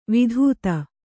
♪ vidhūta